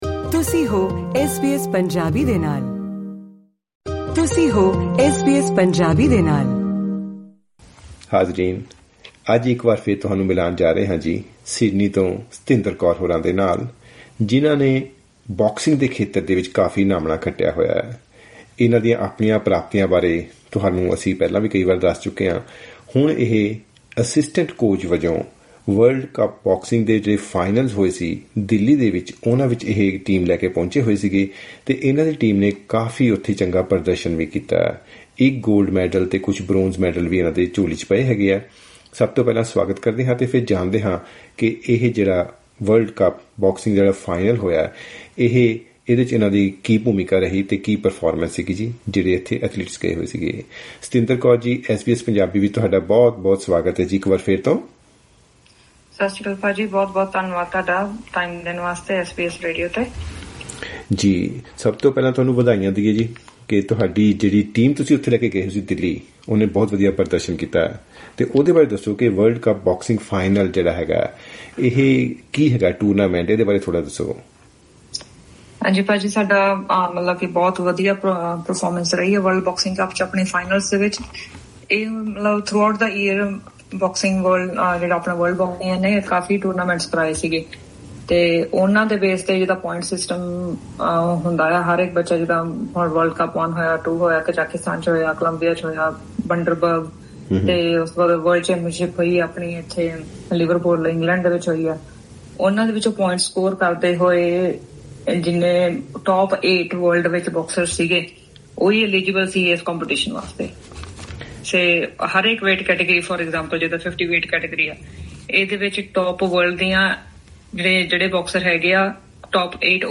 ਖ਼ਾਸ ਗੱਲਬਾਤ